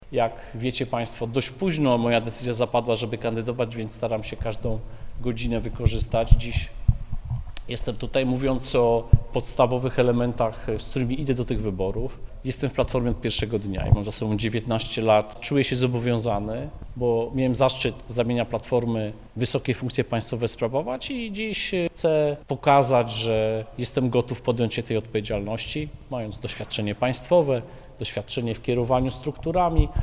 W auli Biblioteki Norwida chwalił marszałek Elżbietę Polak za skutecznie zarządzanie regionem i wieloletnie utrzymanie władzy samorządowej: